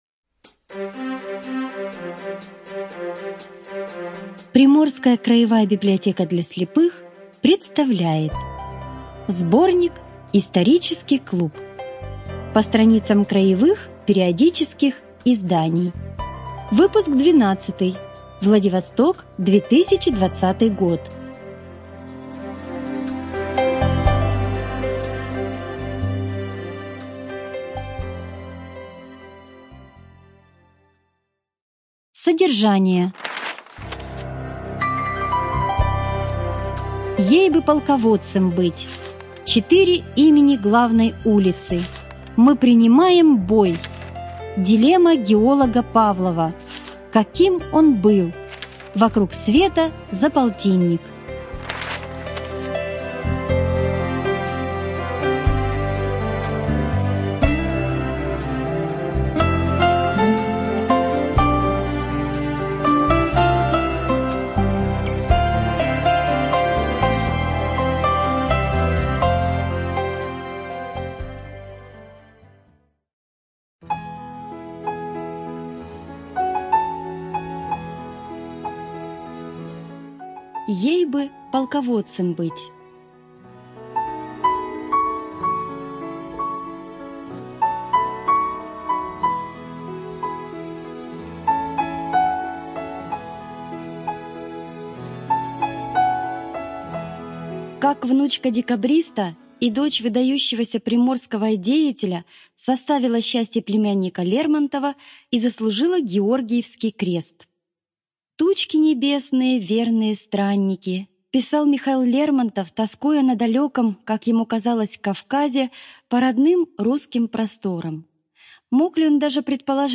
Студия звукозаписиПриморская краевая библиотека для слепых